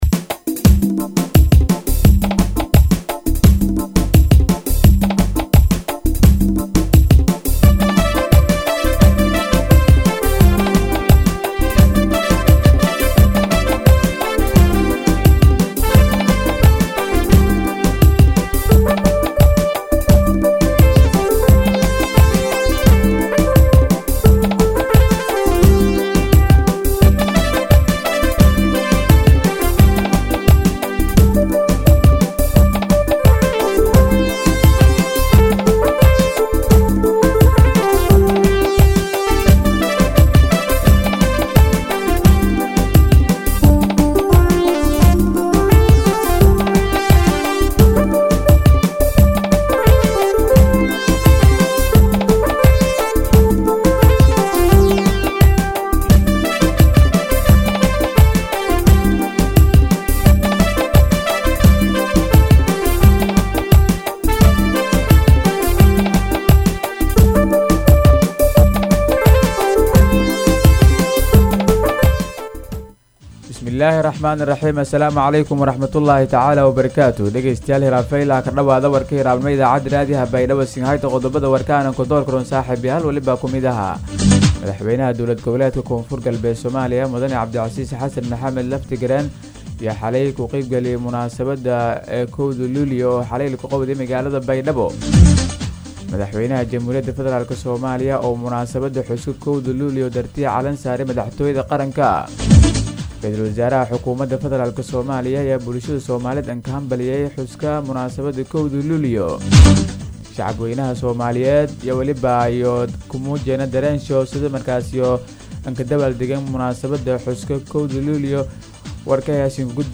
DHAGEYSO:- Warka Subaxnimo Radio Baidoa 1-7-2021